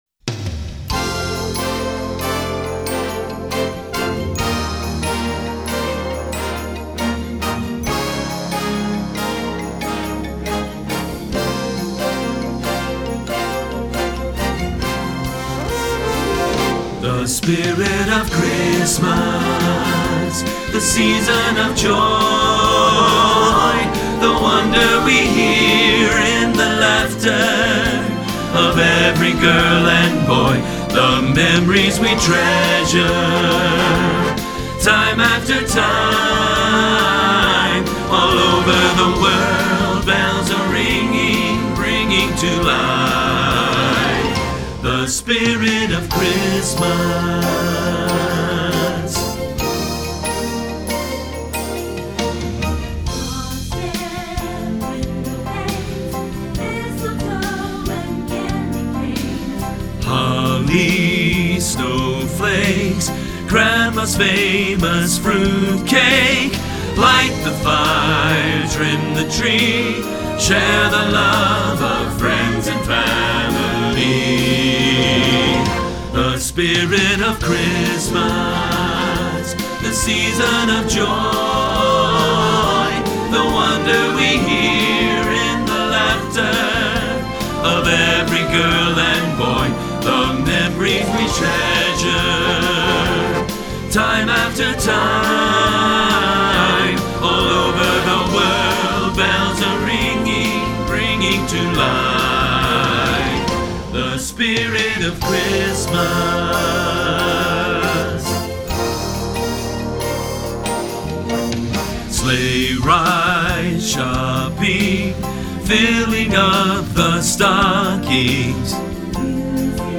The Spirit of Christmas (Bass) — Alum Rock Christian Church